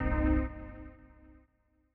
AIR Airport Stab B.wav